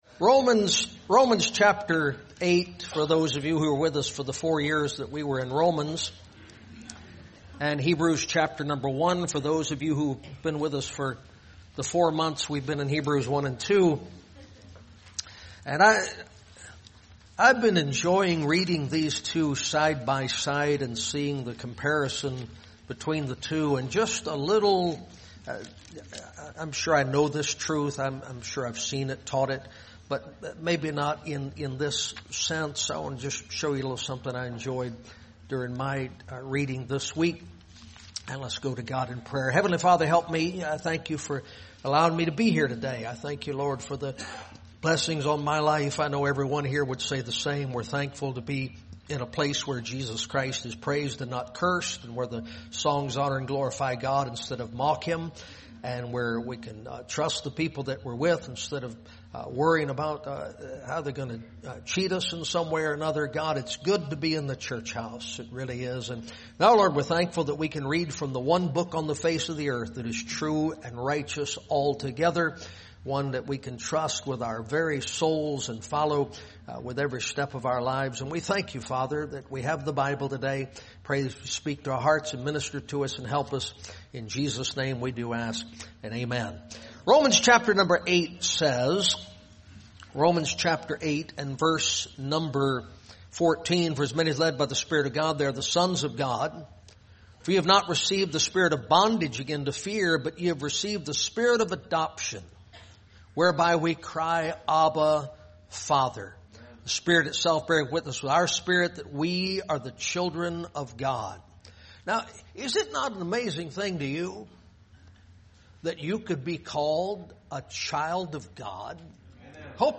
November 12, 2023 pm | Preacher